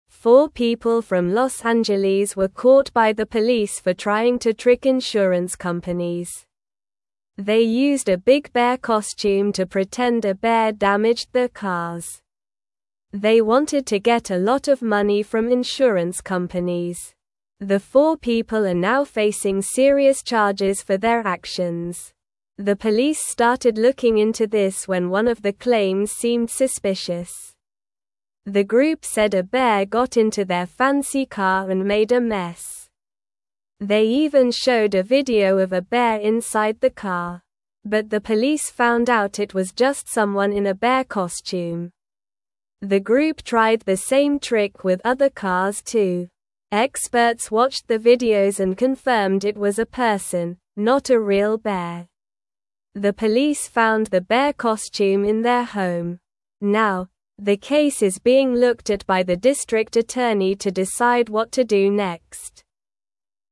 Slow
English-Newsroom-Lower-Intermediate-SLOW-Reading-People-Pretend-Bear-Damaged-Cars-for-Money.mp3